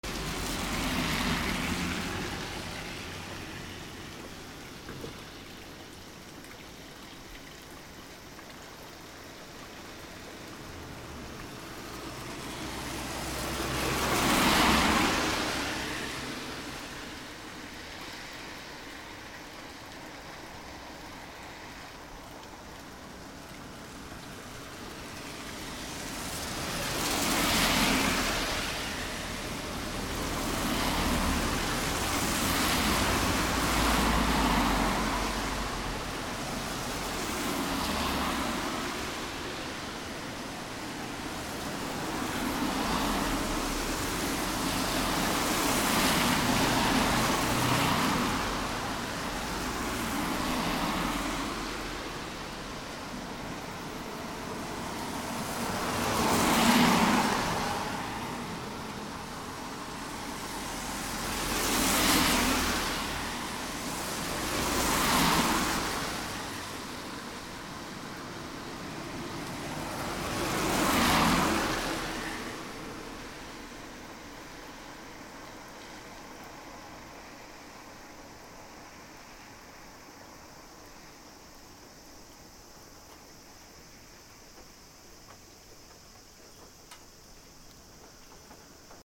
夏 夕立 DL
/ A｜環境音(天候) / A-10 ｜雨
道路 車の通過音 セミあり D100